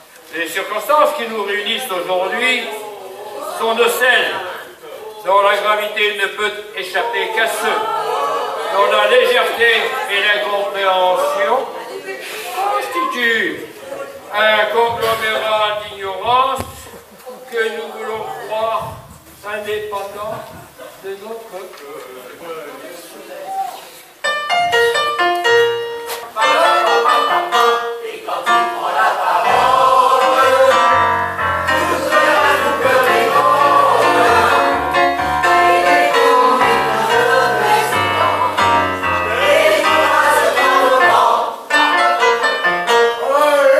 Extrait enregistrement public 2023